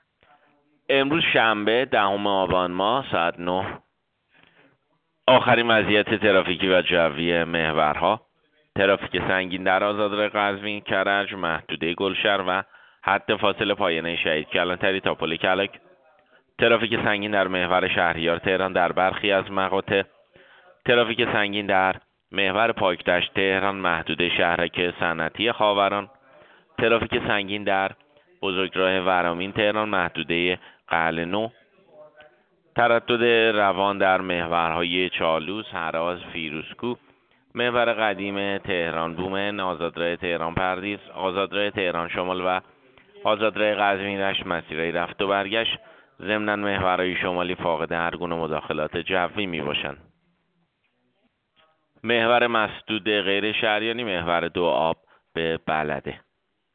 گزارش رادیو اینترنتی از آخرین وضعیت ترافیکی جاده‌ها ساعت ۹ دهم آبان؛